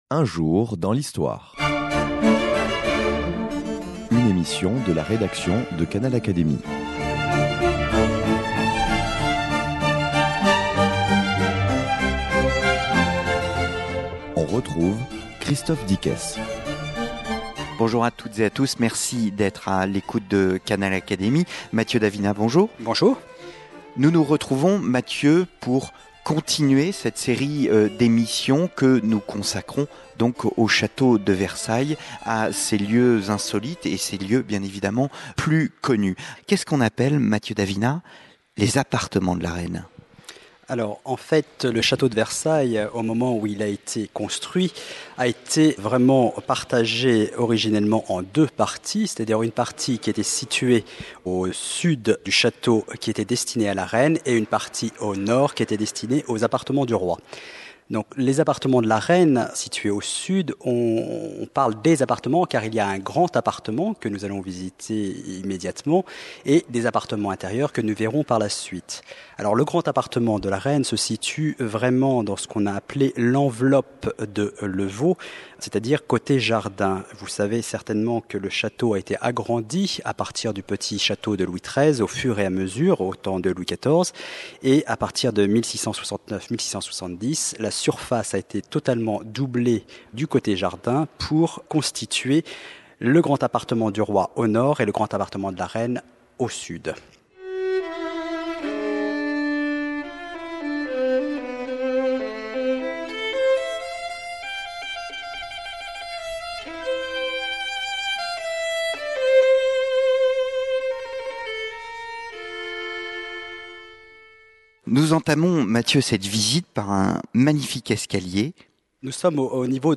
En direct du Château